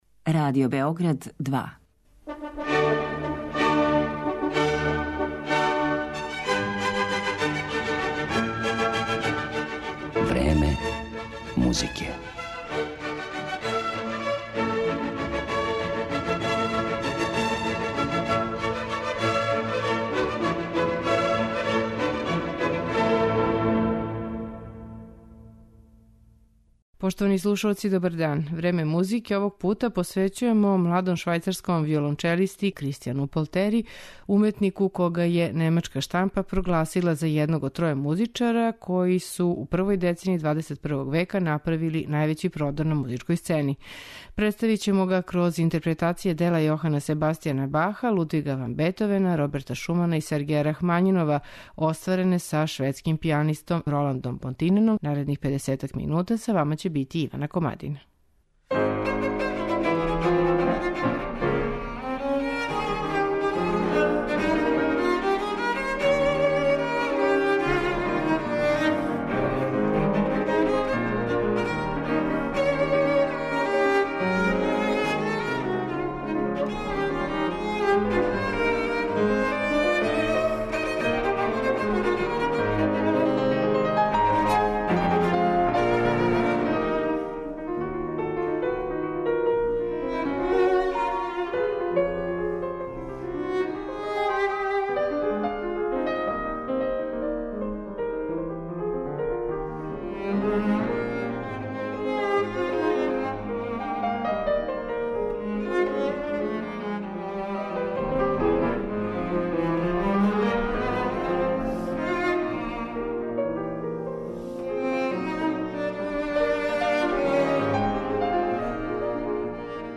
младом швајцарском виолончелисти
шведским пијанистом